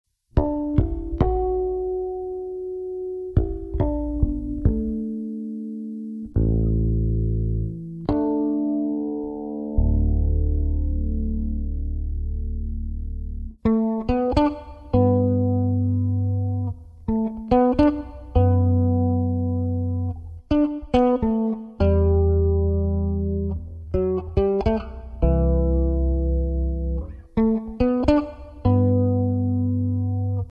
basso elettrico
chitarra